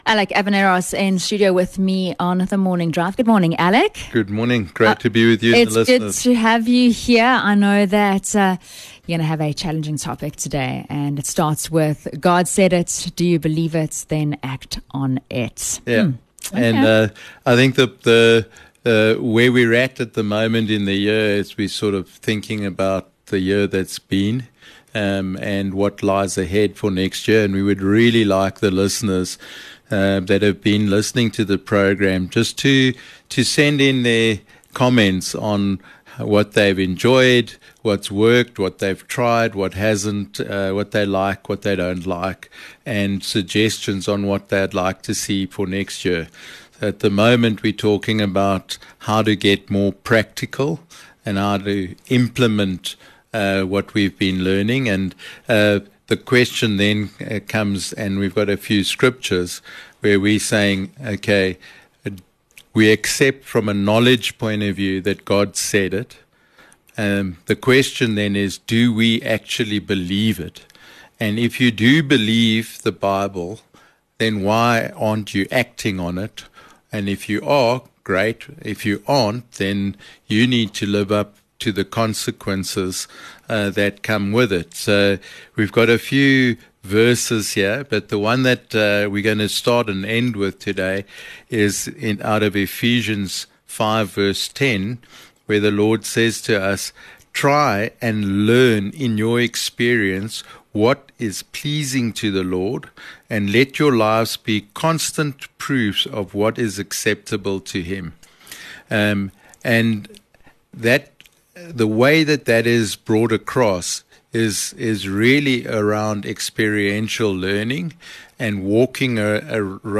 in studio this morning on yet another episode of the Health and Wellness Show. They discuss acting on the plans you have for living a healthy life.